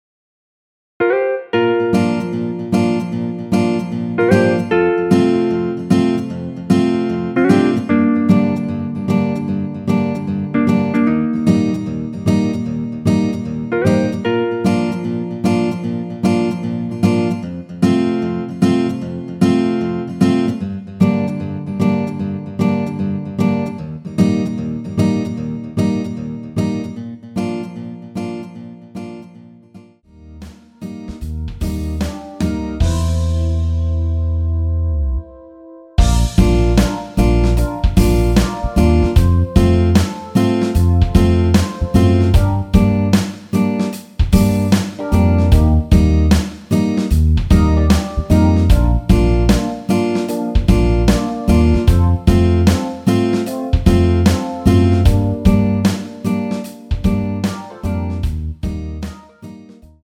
Bb
◈ 곡명 옆 (-1)은 반음 내림, (+1)은 반음 올림 입니다.
앞부분30초, 뒷부분30초씩 편집해서 올려 드리고 있습니다.
중간에 음이 끈어지고 다시 나오는 이유는